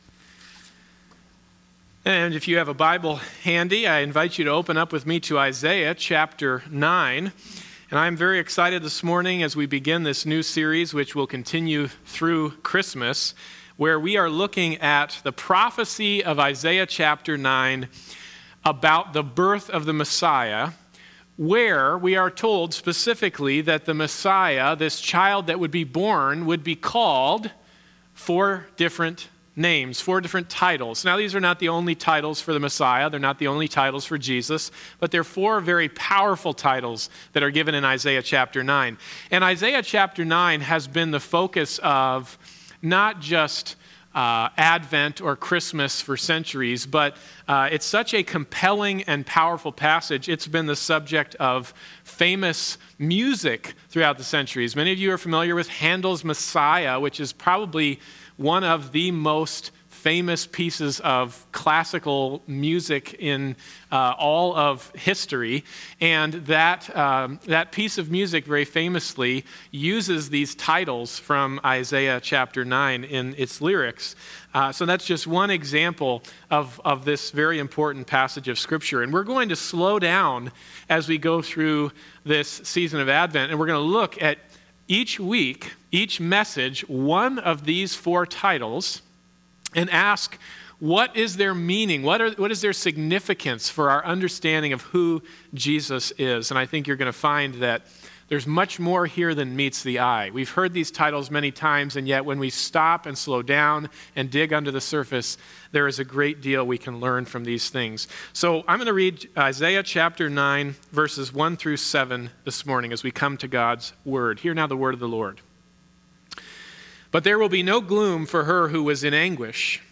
His Name Shall Be Passage: Isaiah 9:1-7 Service Type: Sunday Morning Service « Forgiveness